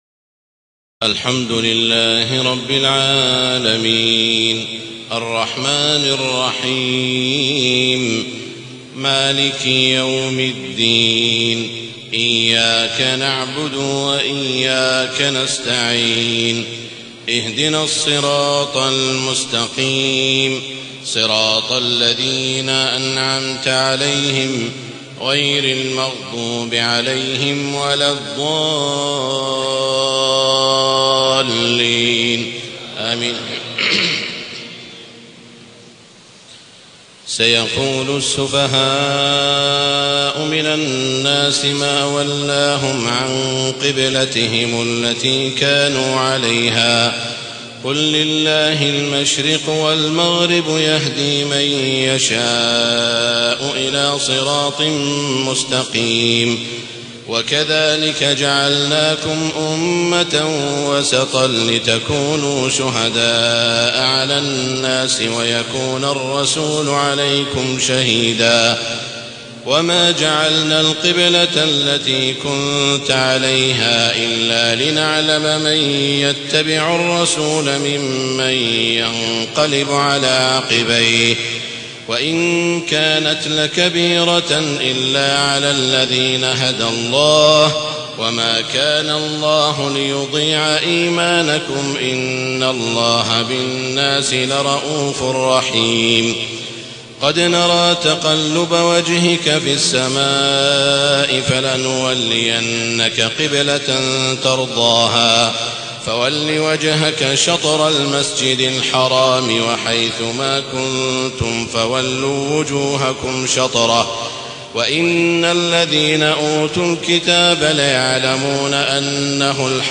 تهجد ليلة 22 رمضان 1432هـ من سورة البقرة (142-218) Tahajjud 22 st night Ramadan 1432H from Surah Al-Baqara > تراويح الحرم المكي عام 1432 🕋 > التراويح - تلاوات الحرمين